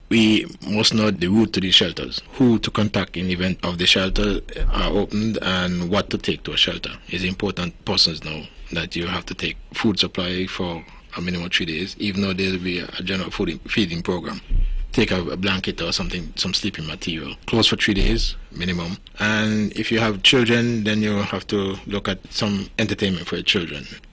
During Wednesday’s On the Mark Programme, Director of the NDMD, Mr. Brian Dyer enlightened the public on how they should prepare if they would need to utilize the various emergency shelters around the island.
What items, however, should be brought to a shelter? Mr. Dyer explains: